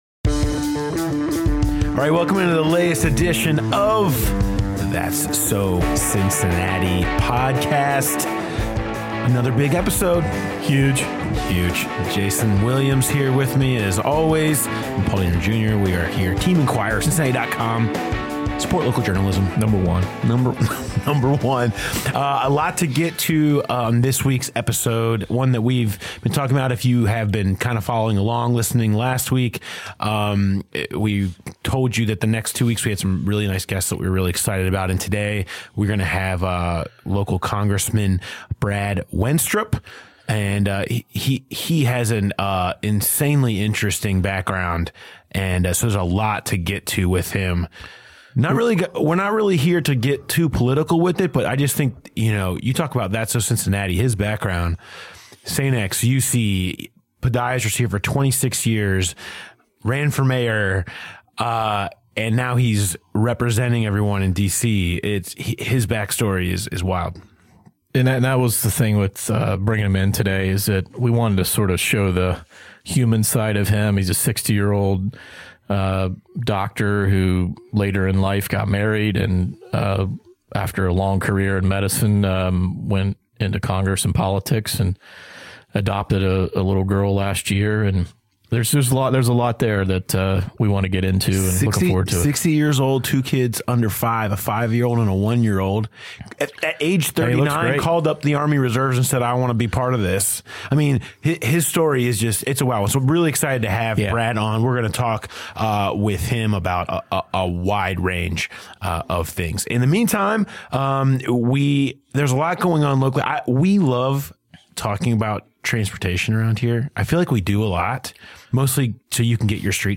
Ahead of the Legislature returning to session on Tuesday for the first time in six weeks, Ohio House Majority Leader Bill Seitz of Green Township joined The Enquirer's That's So Cincinnati podcast to talk about the role GOP lawmakers, DeWine and Acton are playing in reopening the economy.
The Seitz interview begins at the 30:15 mark in the episode.